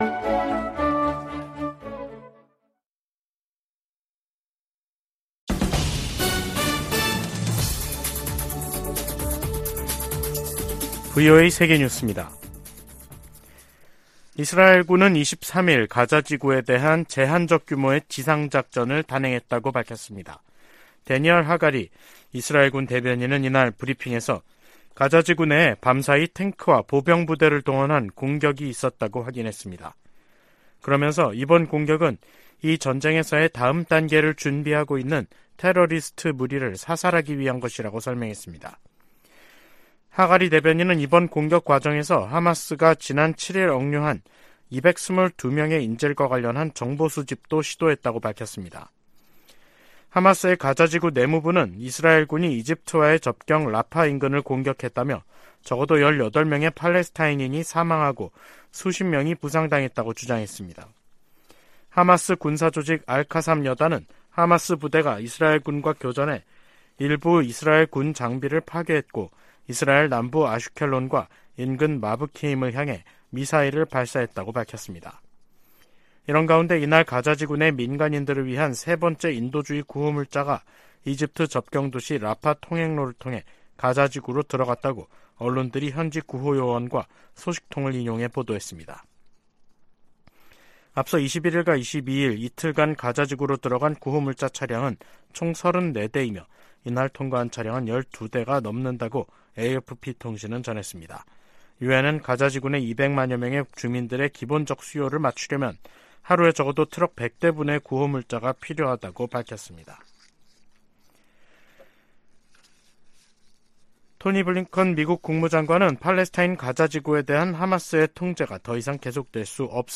VOA 한국어 간판 뉴스 프로그램 '뉴스 투데이', 2023년 10월 23일 3부 방송입니다. 미국 백악관은 북한에서 군사 장비를 조달하려는 러시아의 시도를 계속 식별하고 폭로할 것이라고 강조했습니다. 미국 정부가 북한과 러시아의 무기 거래 현장으로 지목한 라진항에 또다시 컨테이너 더미가 자리했습니다.